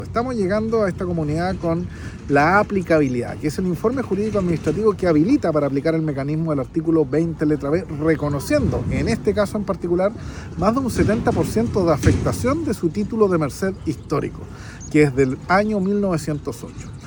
El director nacional de la Conadi, Álvaro Morales, detalló los alcances y que genera la etapa denominada “aplicabilidad”, para el proceso de restitución de tierras, en este caso, para la comunidad mapuche Molcoche de Pitrufquén, región de la Araucanía: